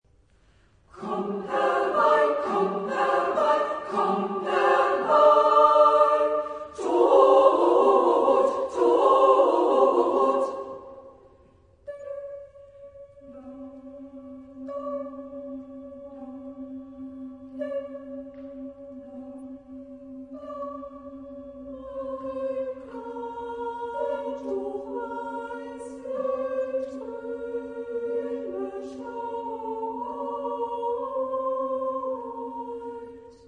Epoque : 20ème s.
Genre-Style-Forme : Profane ; Lied
Type de choeur : SAA (div)  (3 voix égales de femmes )